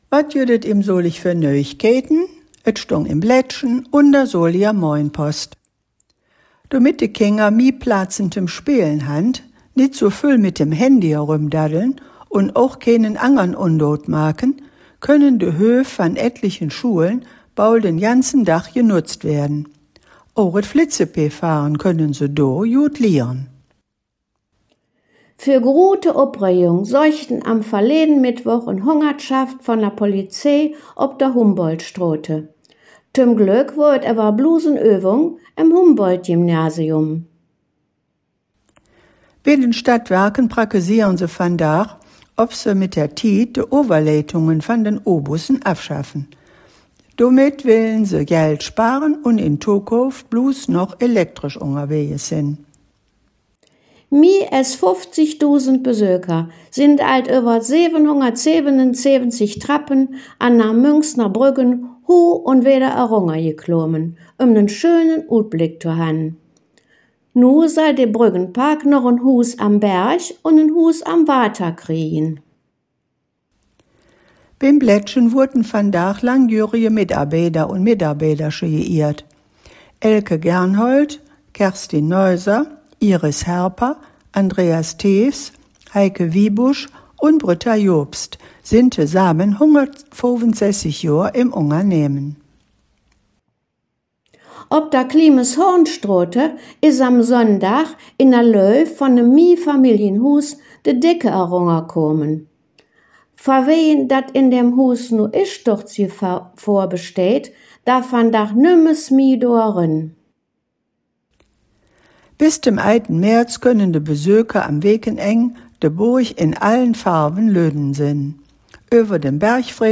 Folge 272 der Nachrichten in Solinger Platt von den Hangkgeschmedden